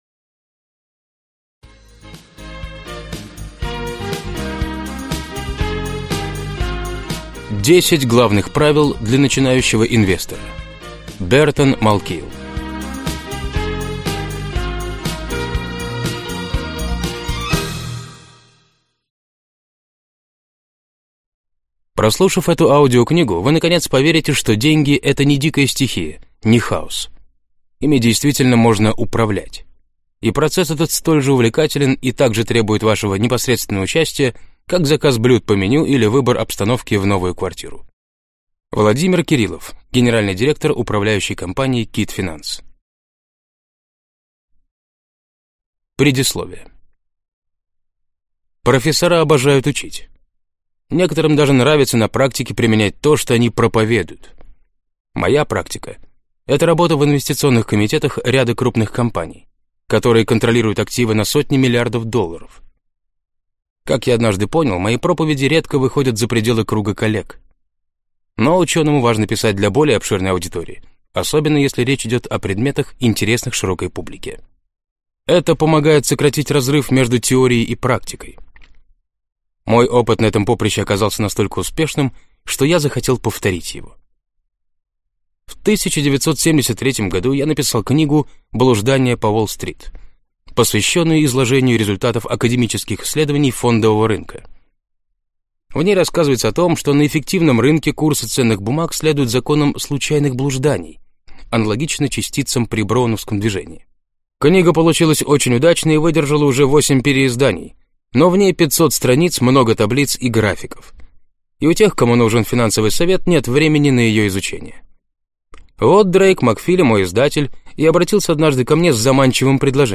Аудиокнига Десять главных правил для начинающего инвестора | Библиотека аудиокниг